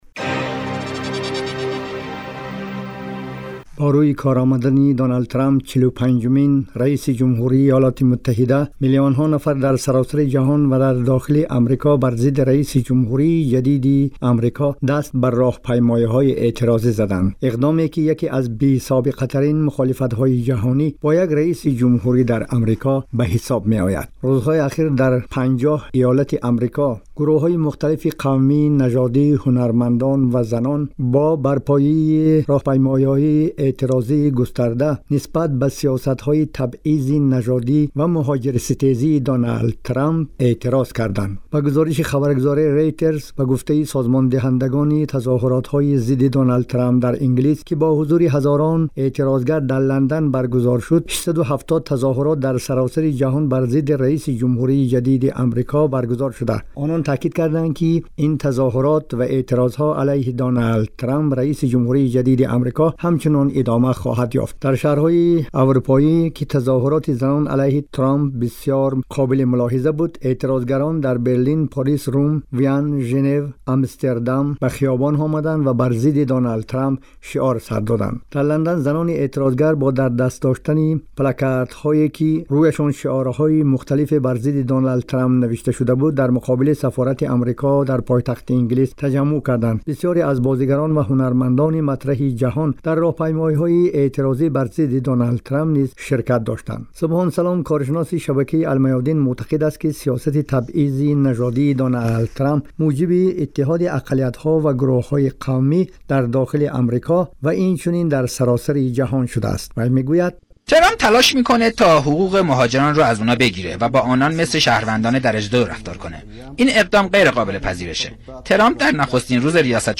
Гузориши вижаи ҳамкоримон